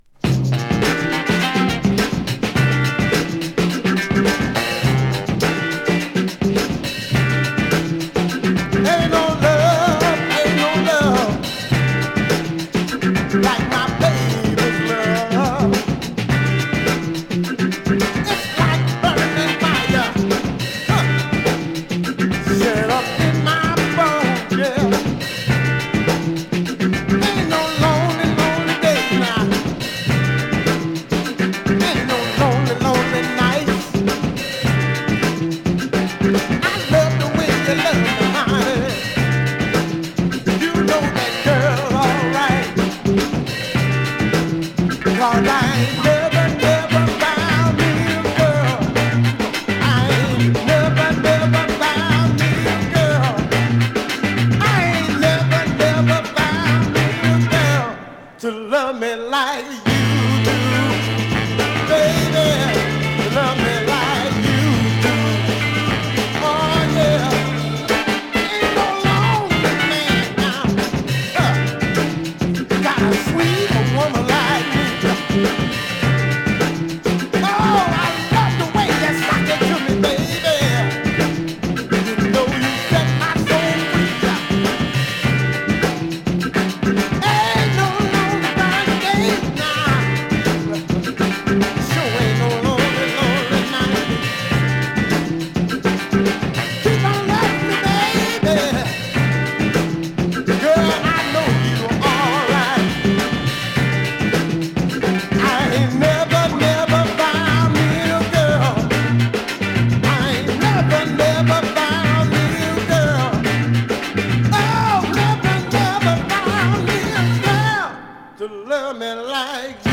現物の試聴（両面すべて録音時間６分３７秒）できます。